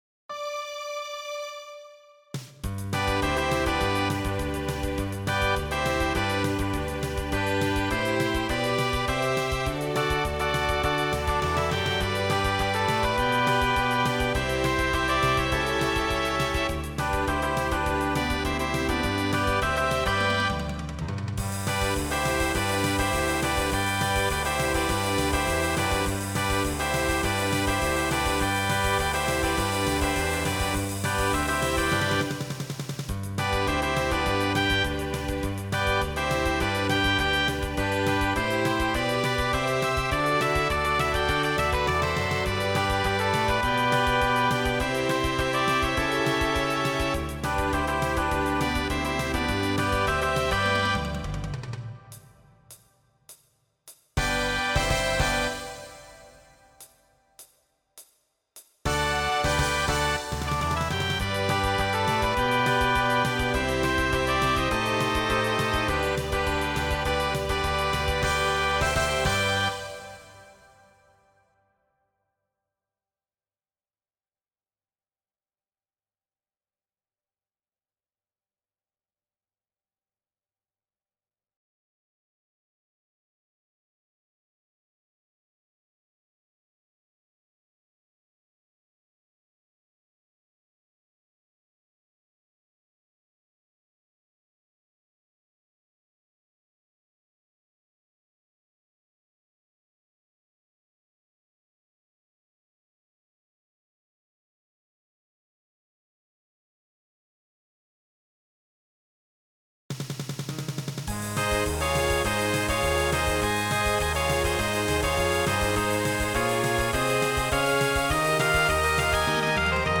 Voicing SATB Instrumental combo Genre Broadway/Film
Mid-tempo